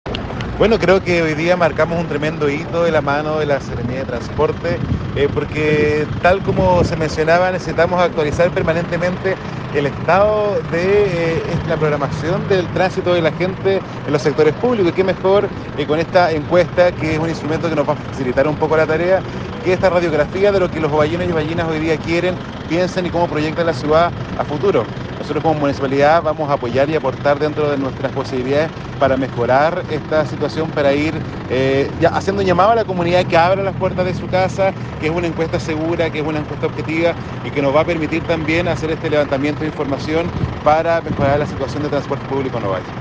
Así lo indica el alcalde de Ovalle, Jhonatan Acuña
JONATHAN-ACUNA-ALCALDE-DE-OVALLE.mp3